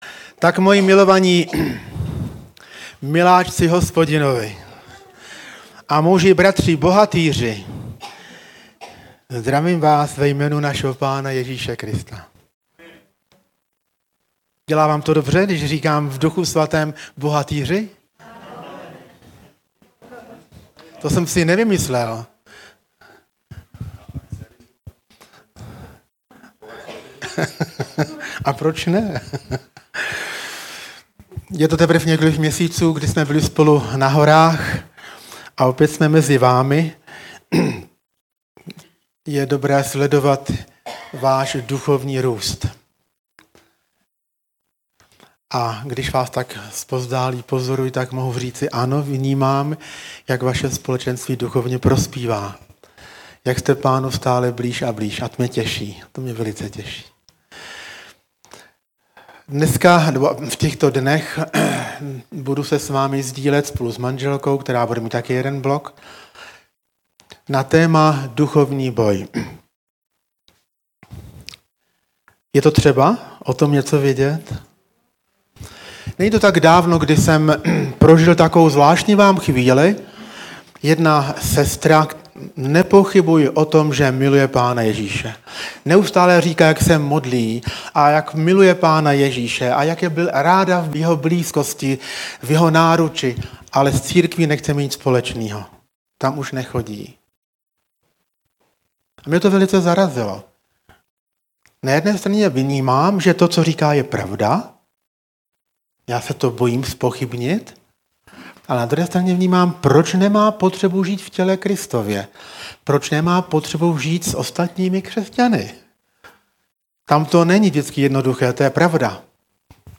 Vypočuj si nedeľné kázne zo zhromaždení Radostného Srdca v Partizánskom.